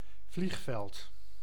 Ääntäminen
Synonyymit luchthaven vlieghaven aërodroom aerodroom Ääntäminen : IPA: [vliːɣ.vɛld] Tuntematon aksentti: IPA: /vli:ɣ.vɛld/ Haettu sana löytyi näillä lähdekielillä: hollanti Käännöksiä ei löytynyt valitulle kohdekielelle.